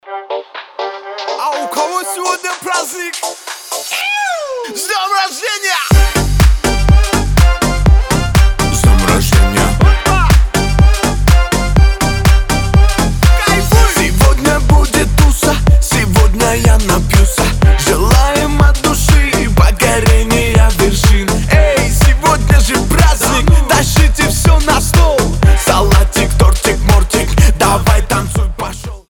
• Качество: 320, Stereo
позитивные
веселые
смешные